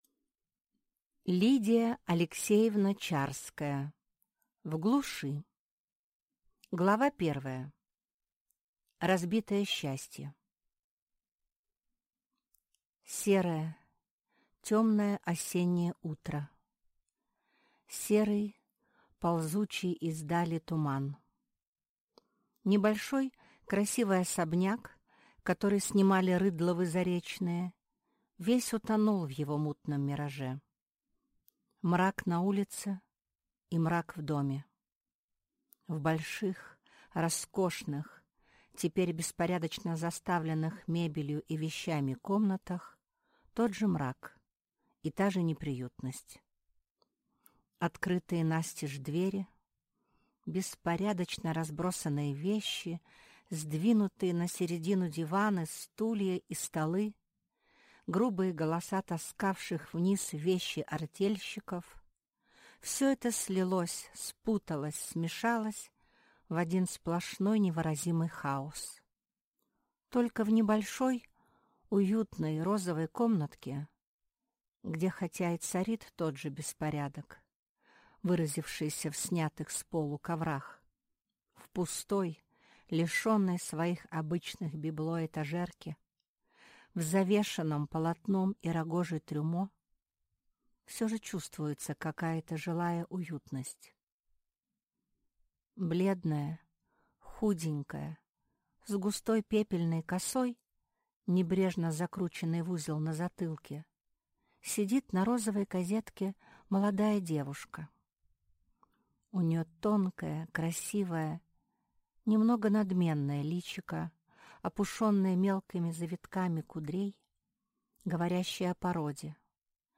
Аудиокнига В глуши | Библиотека аудиокниг